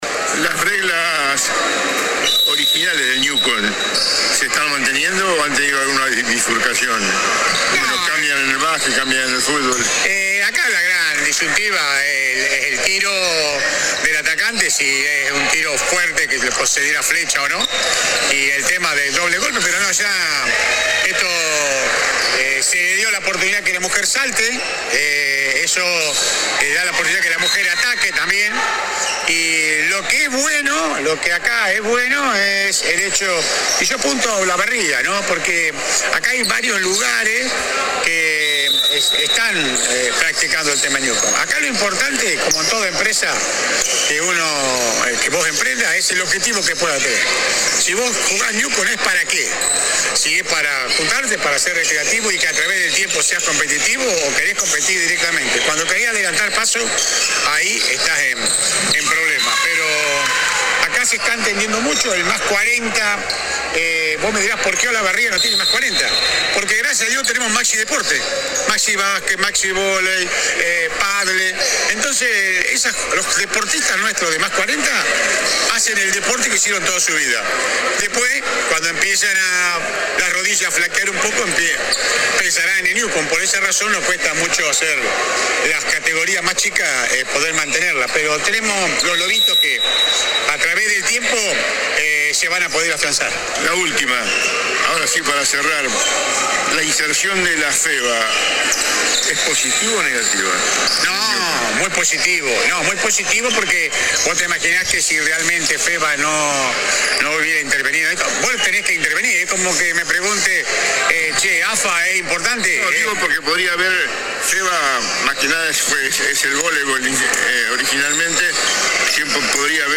AUDIO DE LA ENTREVISTA ( en dos bloques )